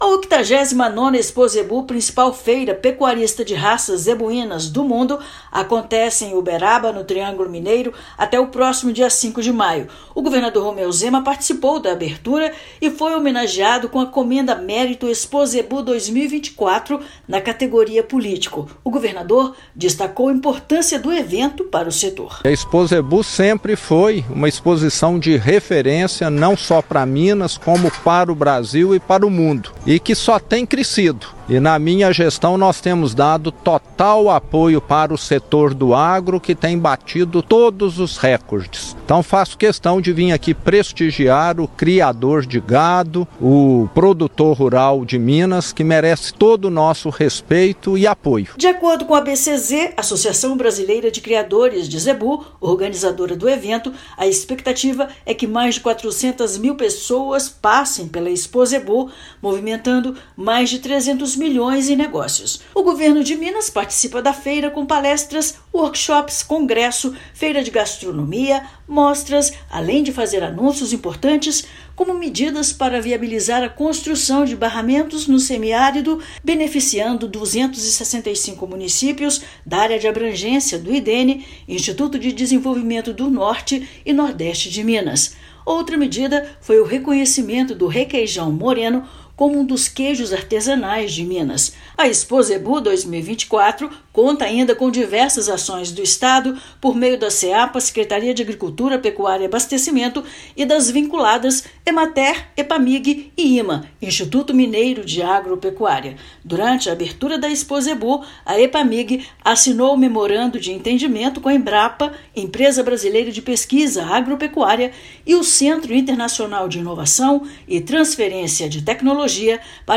[RÁDIO] Governo de Minas promove série de ações para produtores e mercado na 89ª ExpoZebu
Governador participou da abertura da feira e ressaltou iniciativas do governo para fortalecer cadeia produtiva da agropecuária. Ouça matéria de rádio.